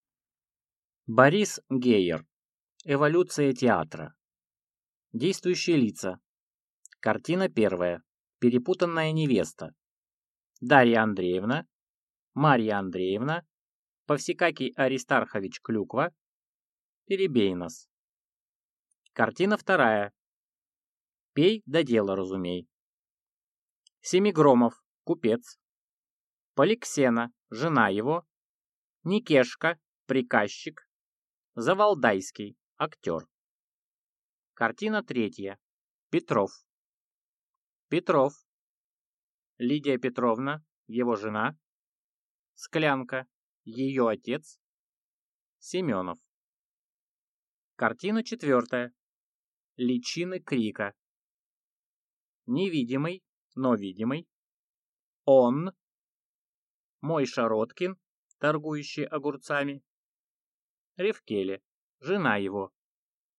Аудиокнига Эволюция театра | Библиотека аудиокниг